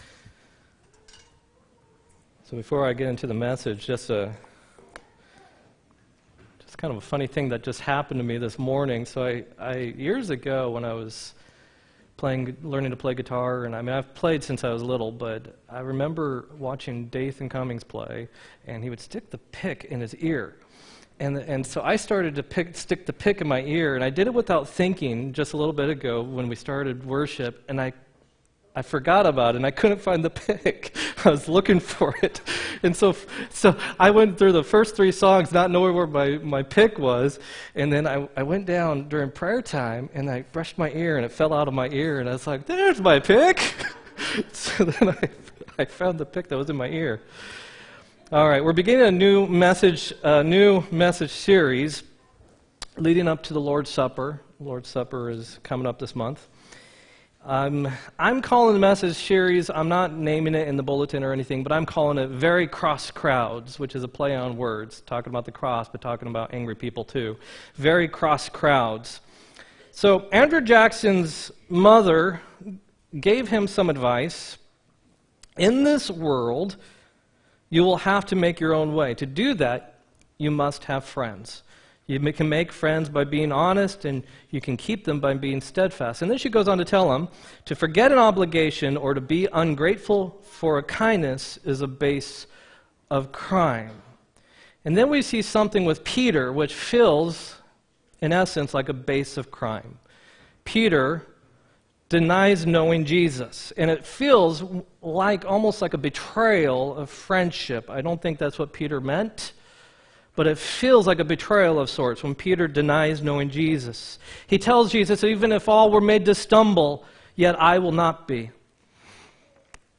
3-10-18 sermon